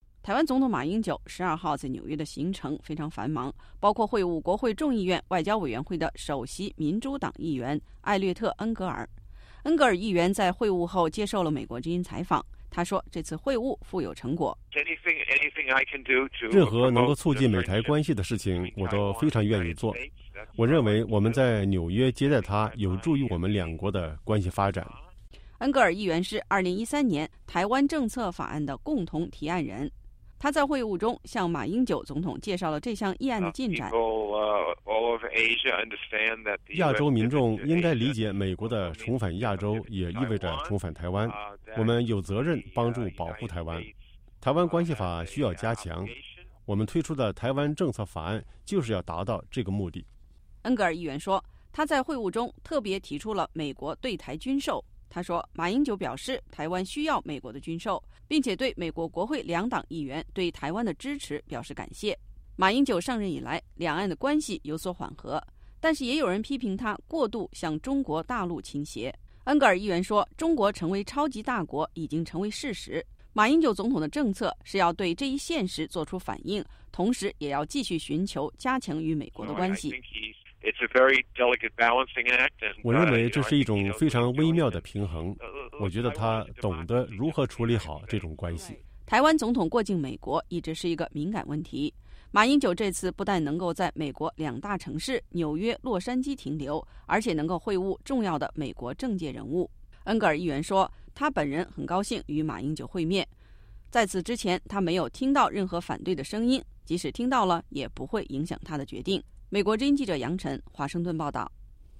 恩格尔议员在会晤后接受美国之音采访。